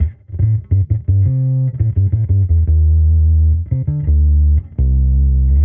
Index of /musicradar/sampled-funk-soul-samples/85bpm/Bass
SSF_PBassProc1_85A.wav